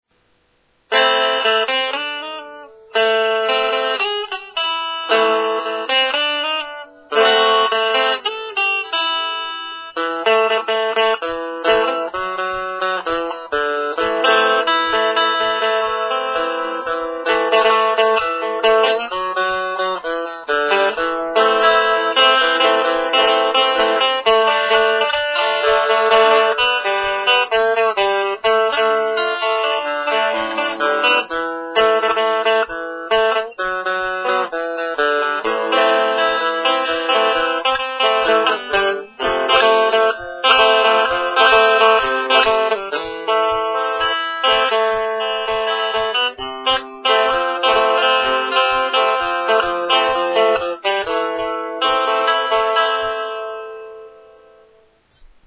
Sample avec ampli Interne